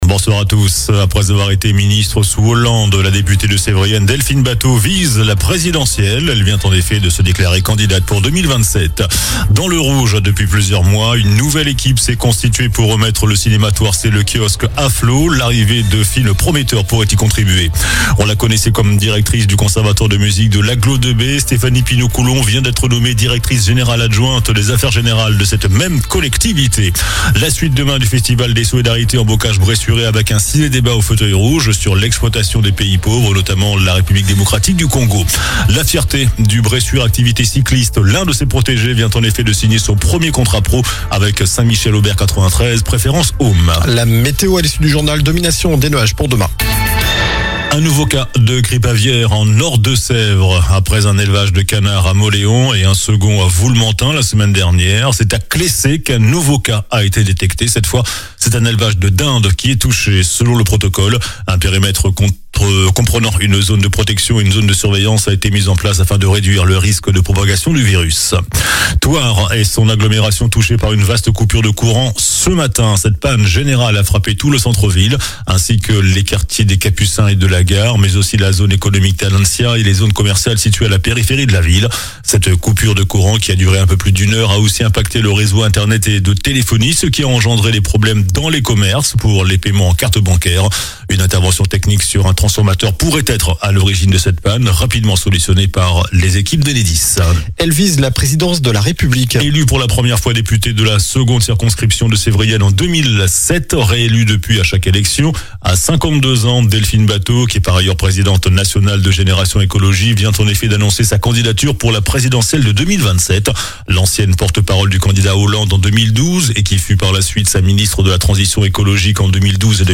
JOURNAL DU MERCREDI 26 NOVEMBRE ( SOIR )